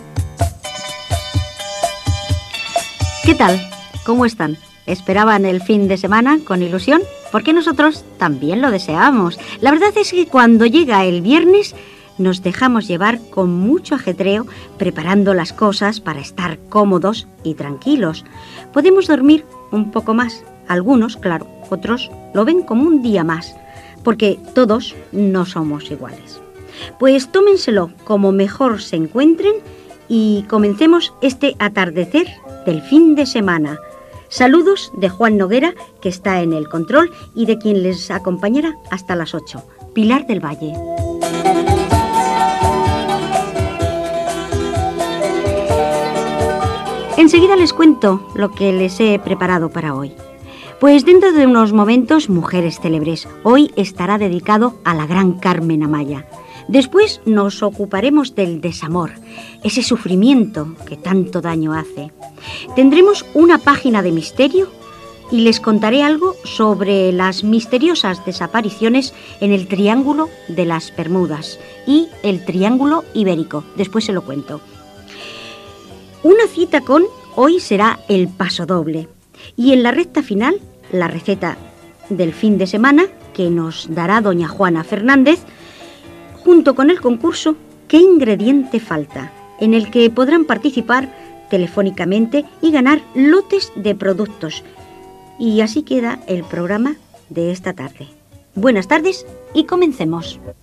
Inici del programa i sumari
Entreteniment